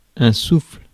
Ääntäminen
France: IPA: [sufl]